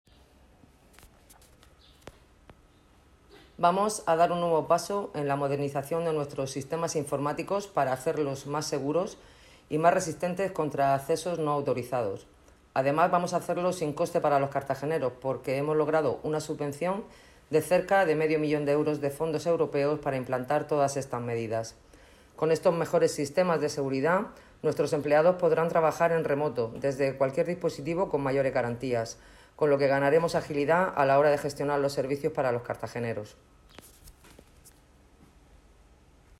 Enlace a Declaraciones de Esperanza Nieto.